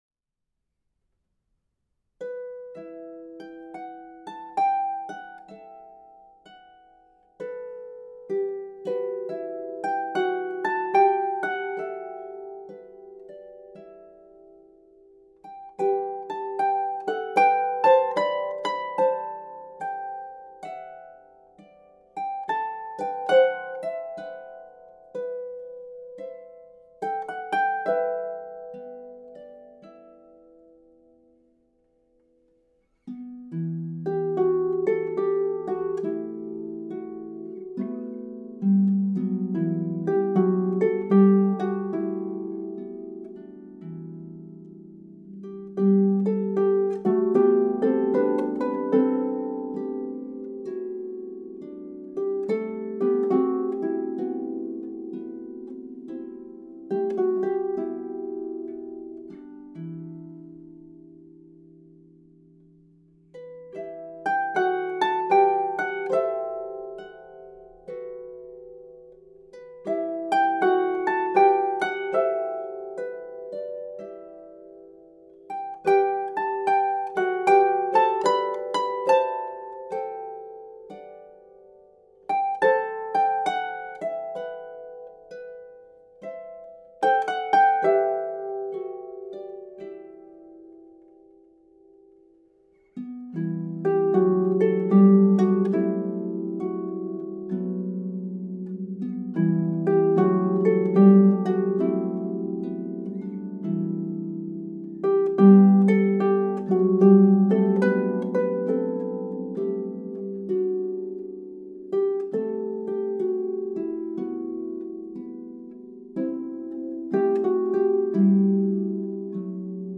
traditional Basque carol
in two versions for solo lever or pedal harp.